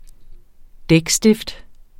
Udtale [ ˈdεg- ]